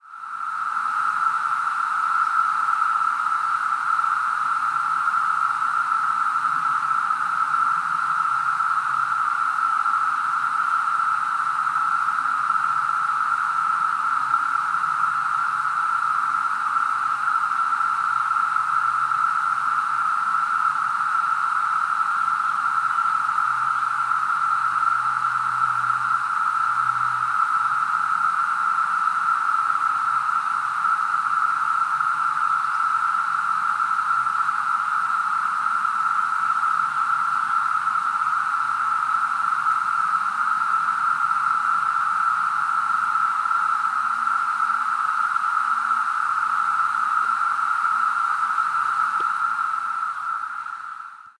Birding in Mendham Township took a second seat to the Brood II Cicadas. The sound at the Ledell Pond trail in Lewis Morris Park was as loud as I have ever heard these creatures. Songs of Scarlet Tanagers and Wood Thrushes were drowned out.
The recording below was made with an iPhone 5 and is solely meant as a brief sample. The sound as it appeared in nature cannot be replicated.